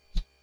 whoosh3.wav